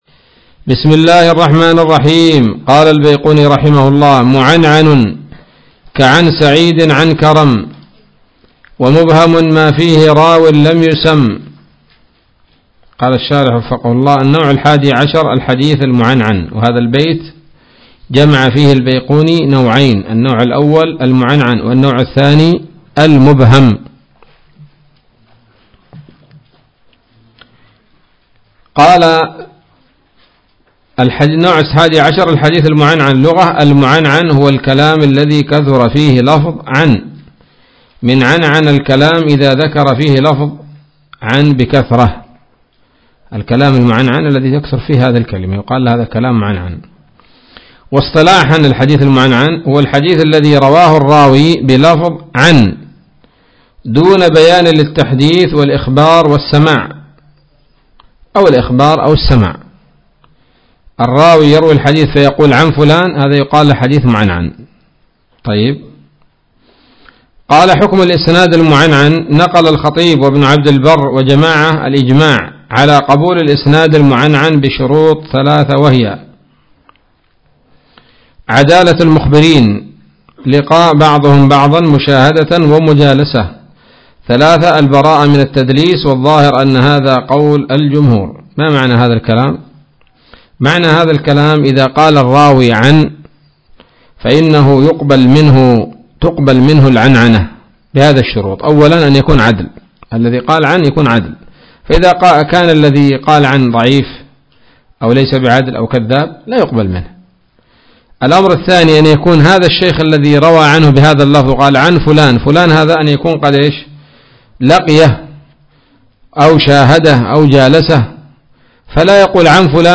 الدرس السابع عشر من الفتوحات القيومية في شرح البيقونية [1444هـ]